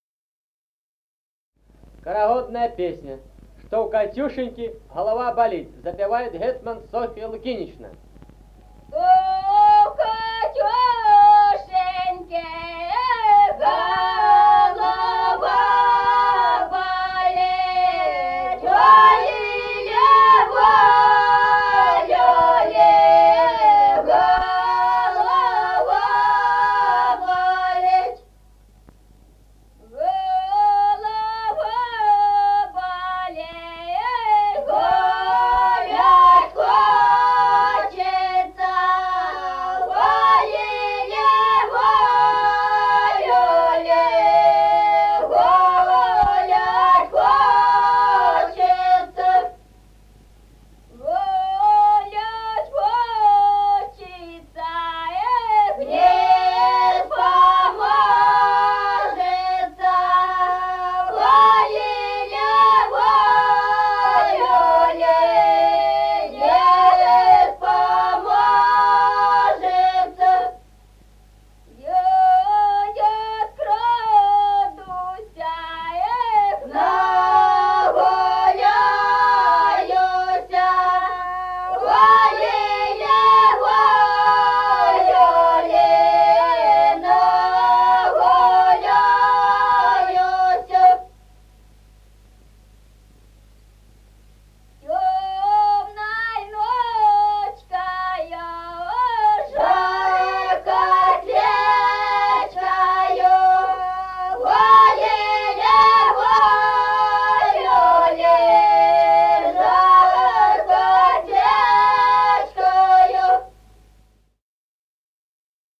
Народные песни Стародубского района «Что у Катюшеньки», карагодная.
(подголосник)
(запев).
1953 г., с. Мишковка.